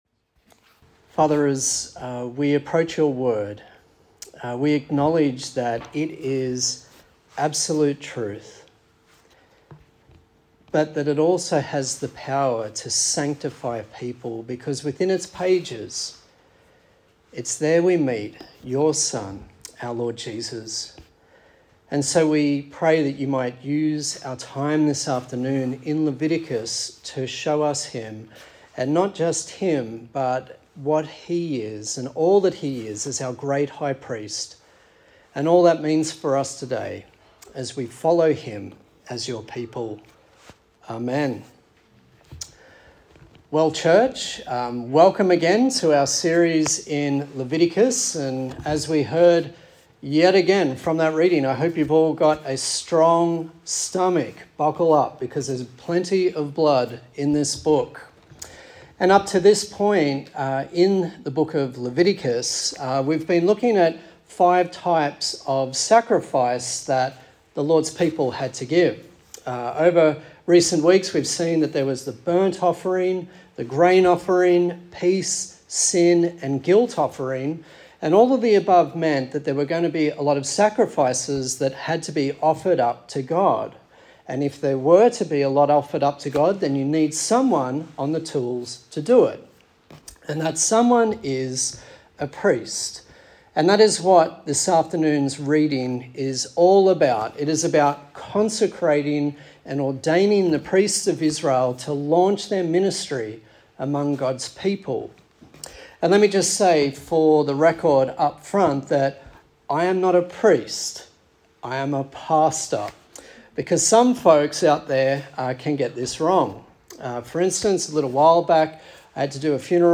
Service Type: Sunday Service A sermon in the series on the book of Leviticus